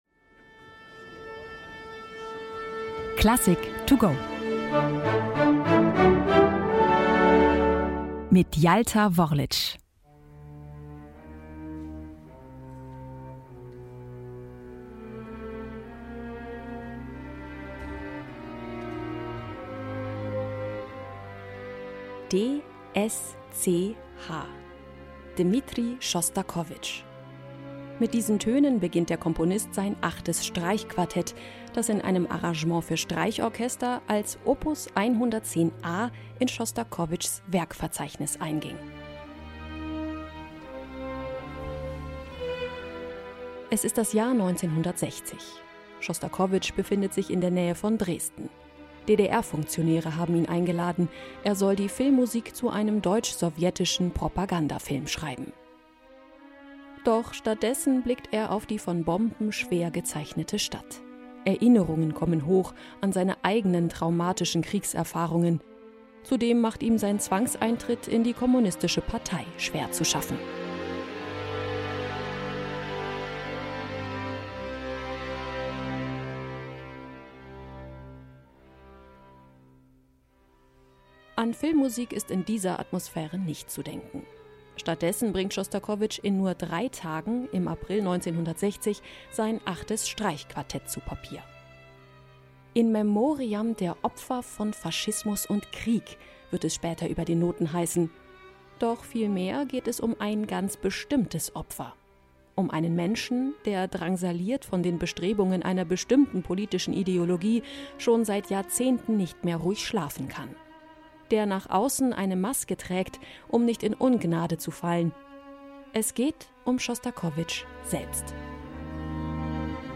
Werkeinführung für unterwegs